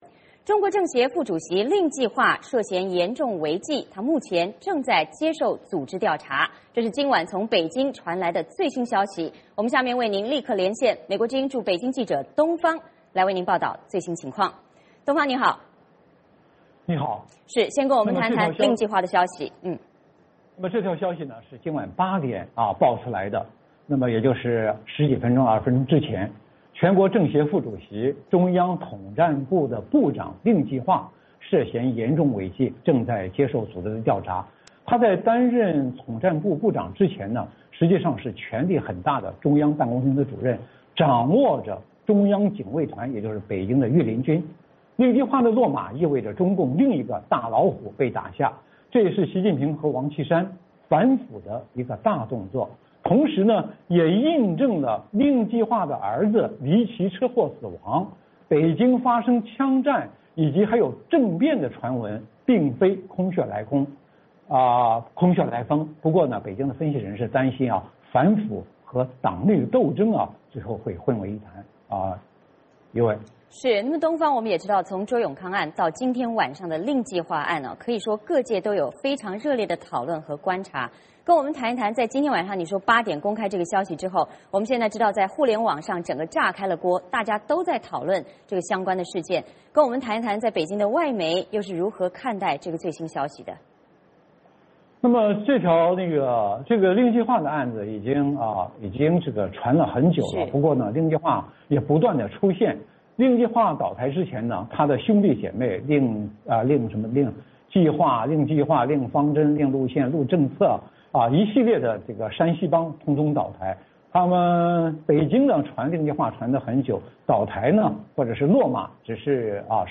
VOA连线：令计划开始接受调查